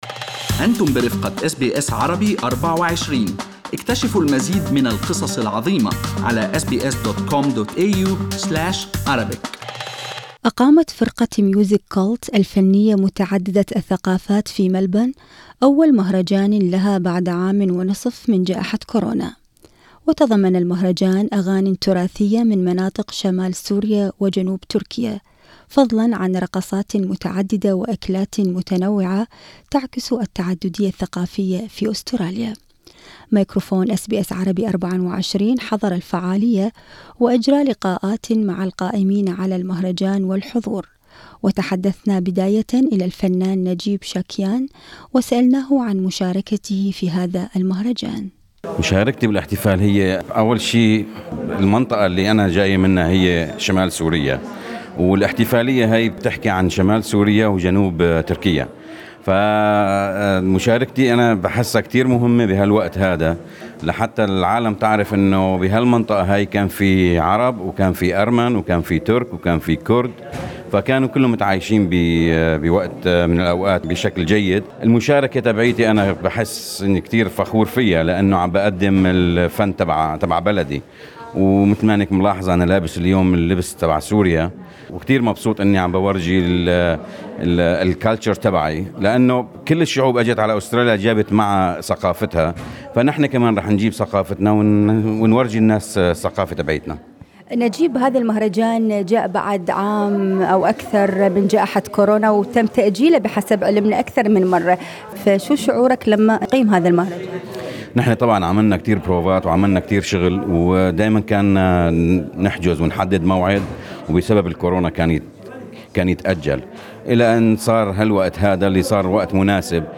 وتضمن المهرجان أغاني تراثية من مناطق شمال سوريا وجنوب تركيا، فضلا عن رقصات متعددة وأكلات متنوعة تعكس التعددية الثقافية في أستراليا. ميكرفون أس بي اس عربي24 حضر الفعالية وأجرى لقاءات مع القائمين على المهرجان والحضور.